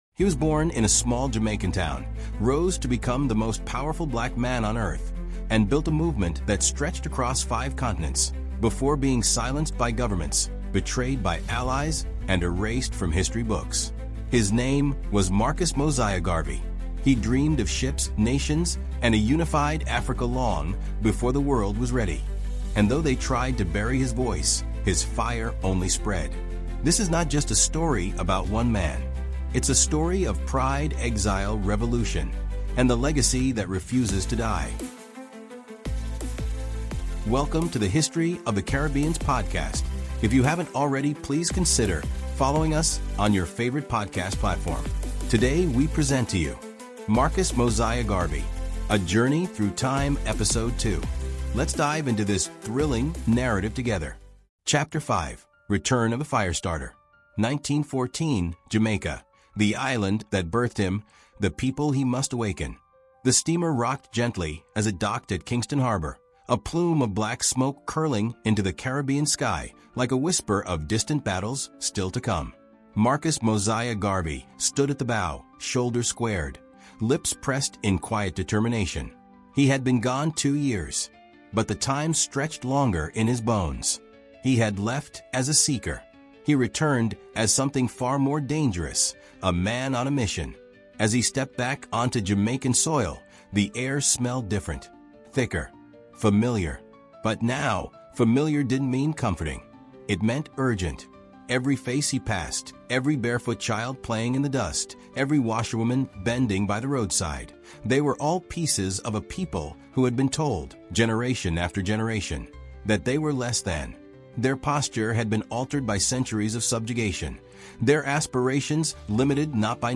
Through rich storytelling and immersive narration, discover how Garvey inspired generations—from African presidents and civil rights leaders to Rastafarians, poets, and musicians across the globe.